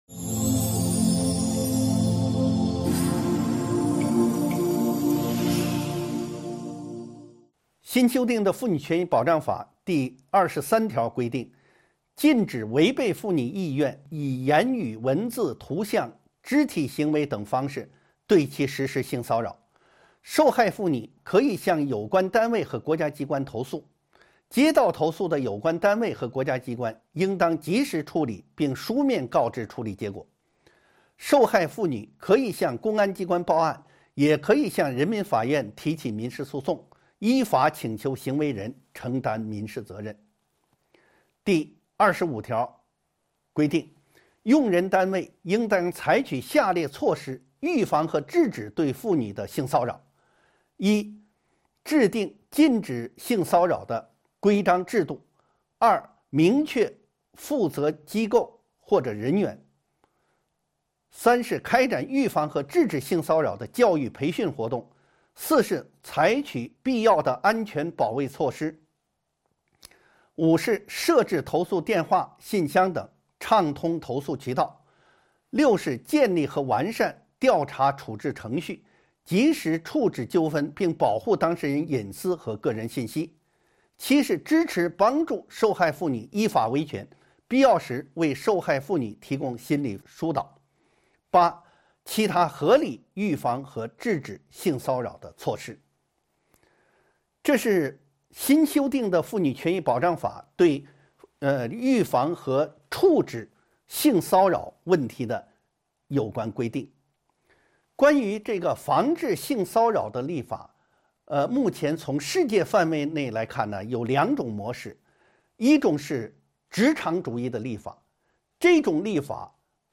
音频微课：《中华人民共和国妇女权益保障法》14.性骚扰的认定及其防治